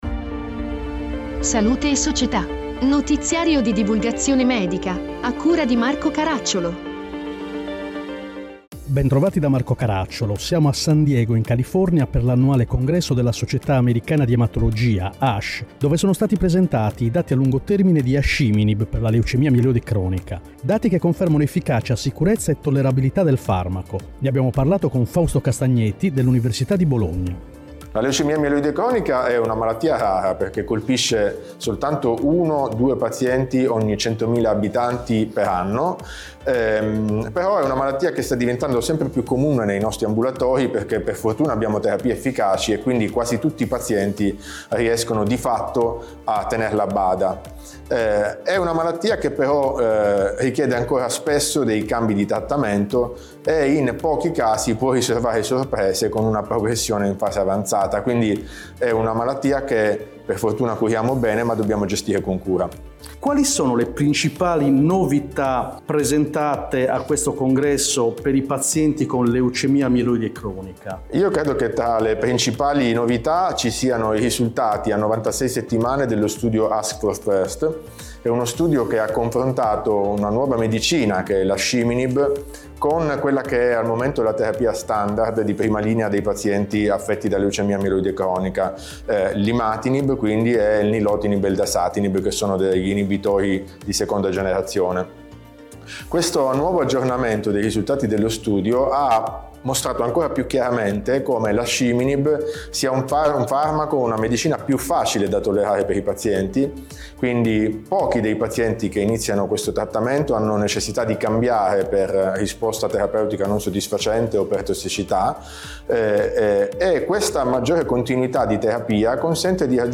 Report da San Diego, California, per l’annuale congresso della Società Americana di ematologia, ASH, dove sono stati presentati i dati a lungo termine di Asciminib per la leucemie mieloide cronica.